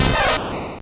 Cri de Pikachu dans Pokémon Or et Argent.